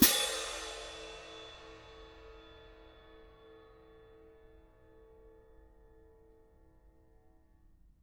cymbal-crash1_mp_rr2.wav